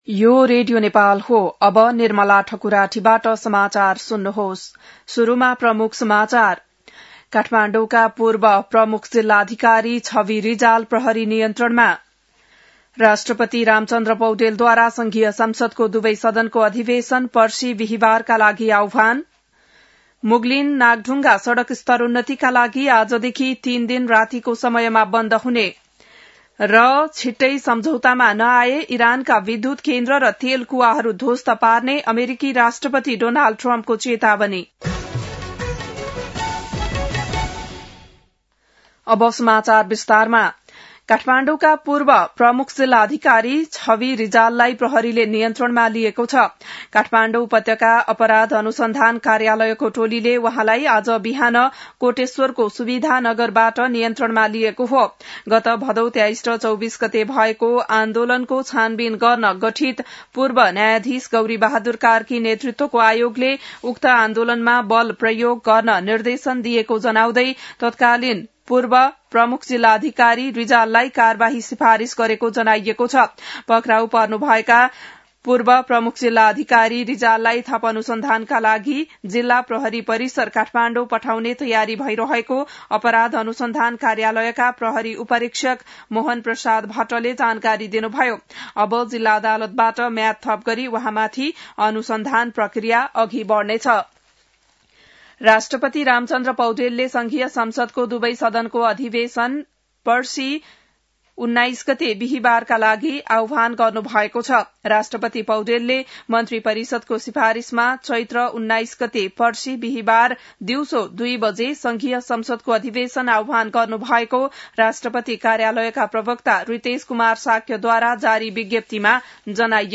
बिहान ९ बजेको नेपाली समाचार : १७ चैत , २०८२